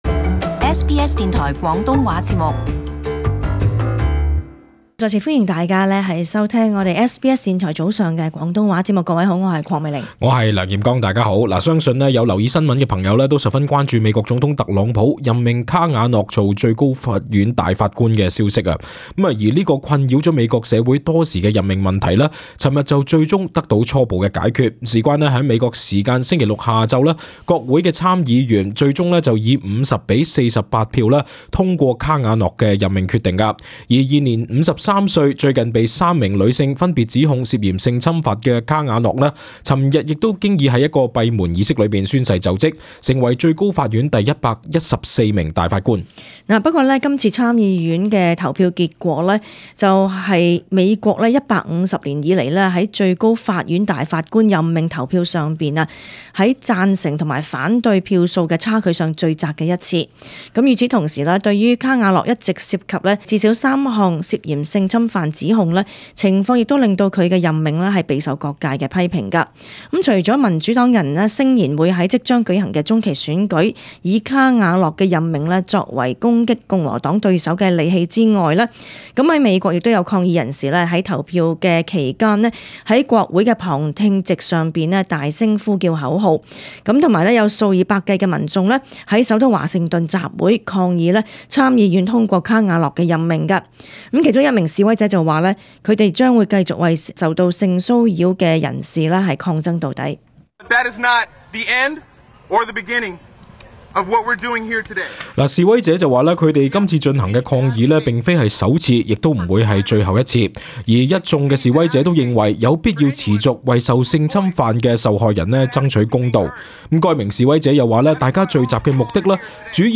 【時事報導】美國民眾抗議卡瓦諾就任最高法院大法官